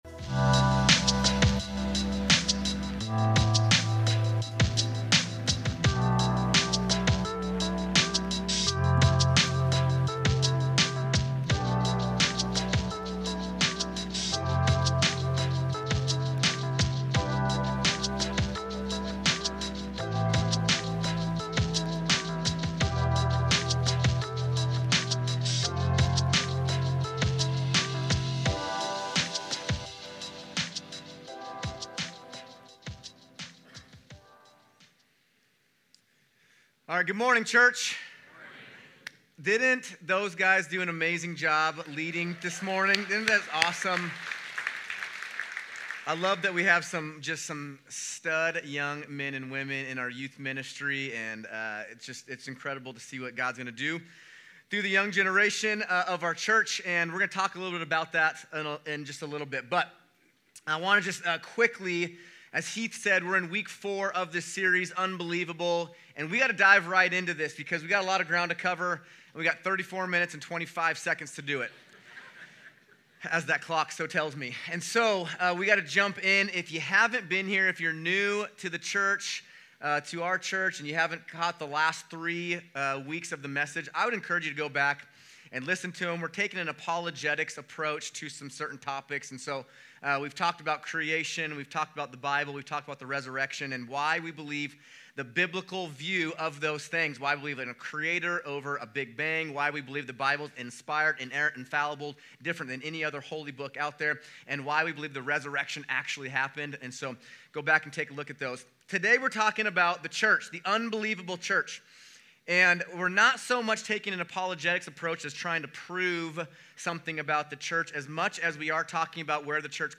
Week+Four+unBelievable+Sermon.mp3